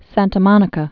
(săntə mŏnĭ-kə)